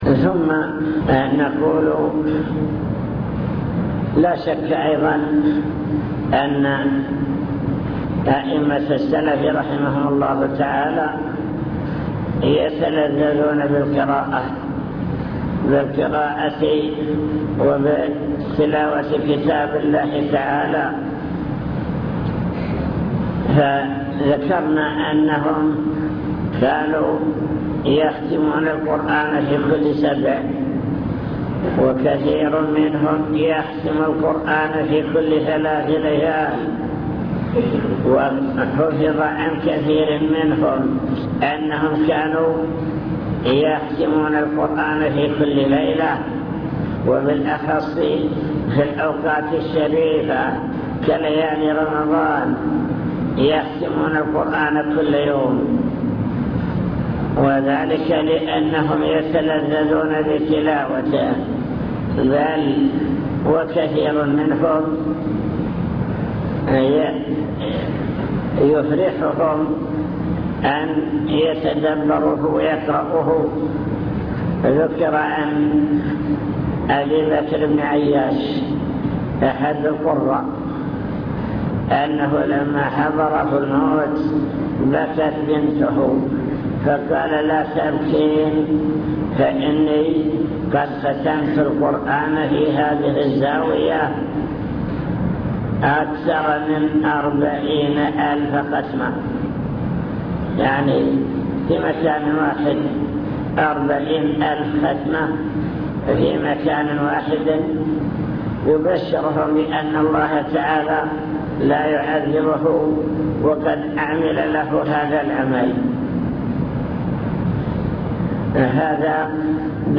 المكتبة الصوتية  تسجيلات - محاضرات ودروس  محاضرة في النصرية أحوال سلف الأمة في العبادة